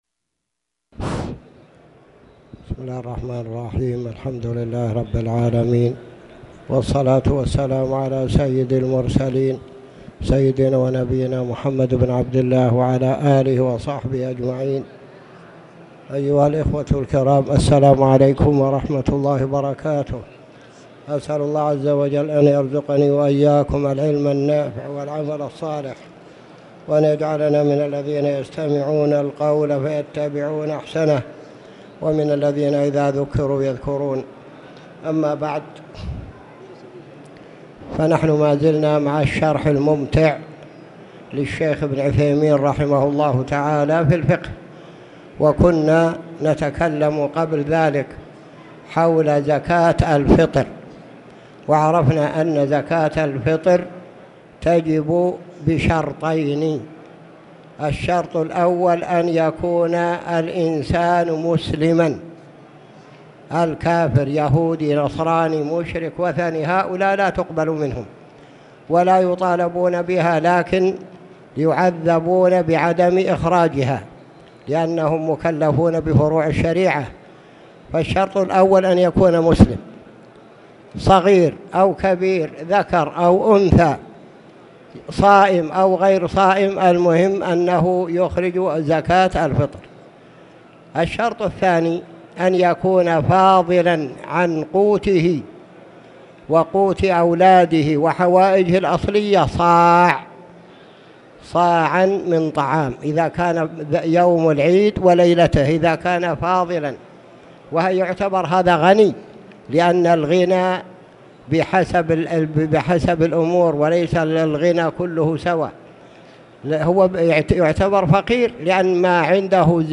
تاريخ النشر ٢٨ جمادى الآخرة ١٤٣٨ هـ المكان: المسجد الحرام الشيخ